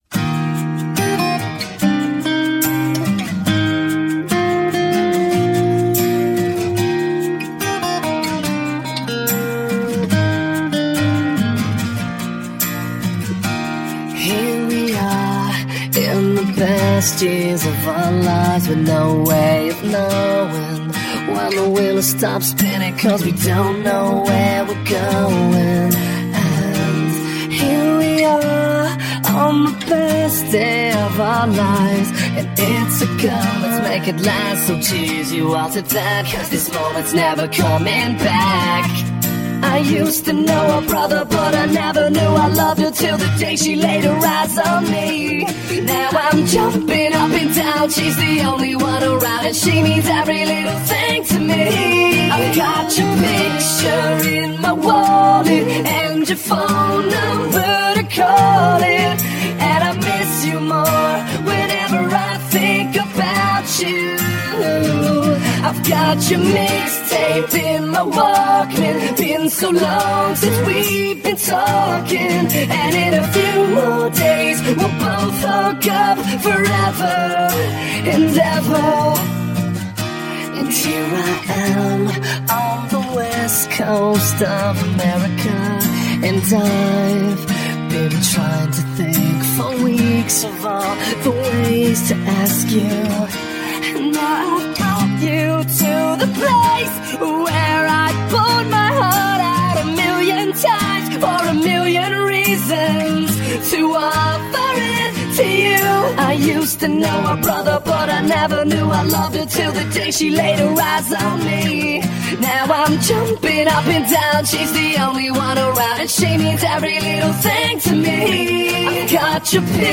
Christian pop punk duo
*Converted for key of Bb instrument